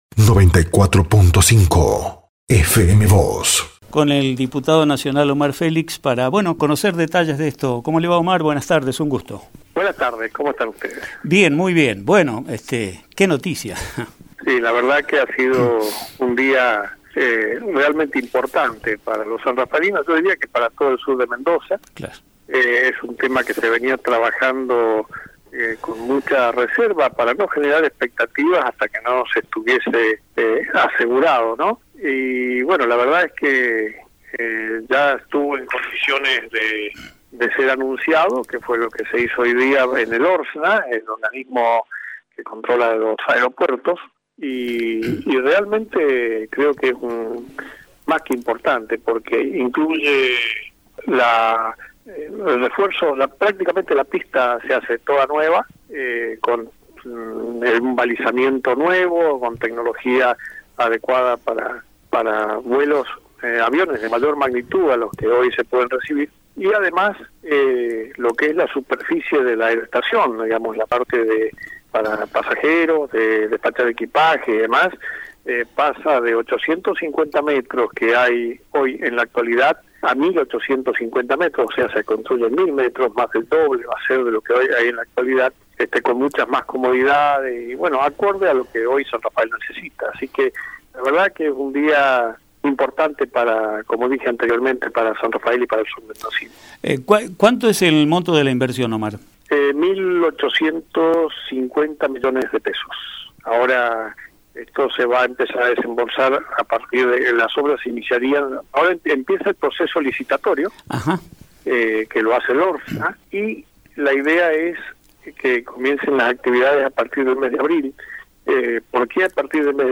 Por su parte, Omar Félix dijo a FM Vos (94.5) y Diario San Rafael que “es un tema que se venía trabajando con mucha reserva y la verdad que estuvo en condiciones de ser anunciado.